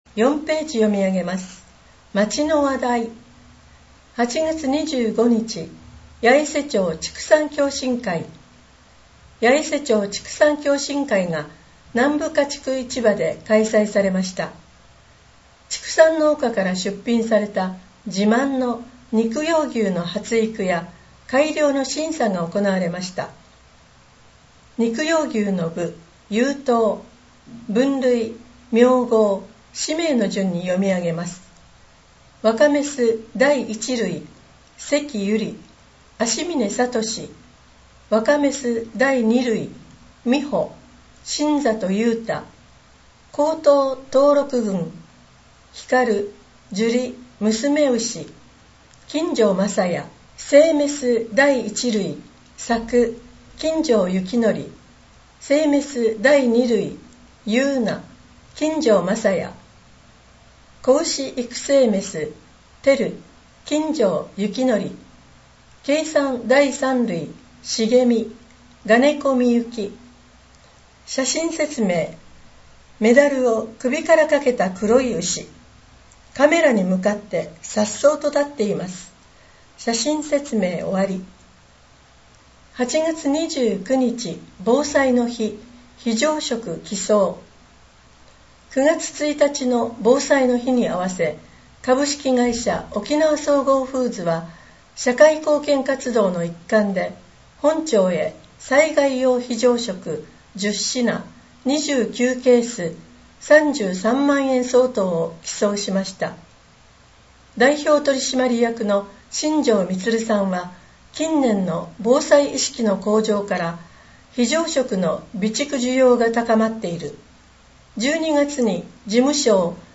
声の「広報やえせ」　令和6年10月号226号